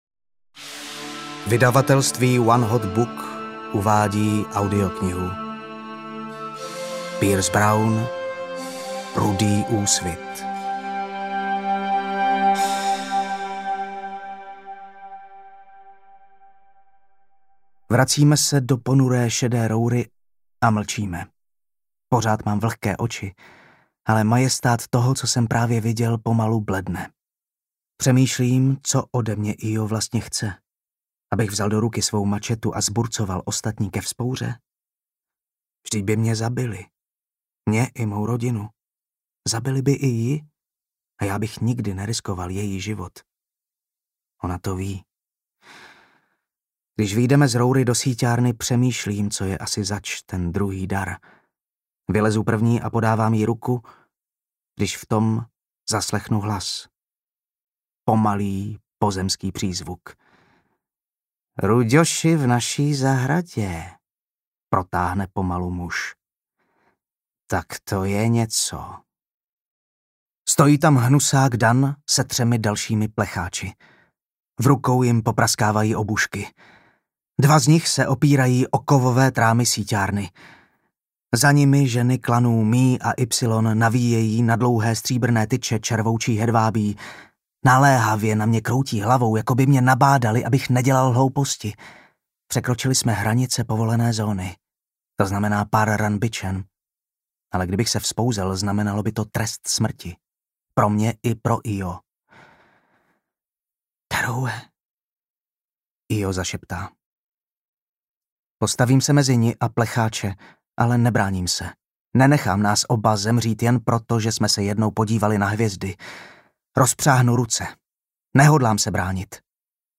Rudý úsvit audiokniha
Ukázka z knihy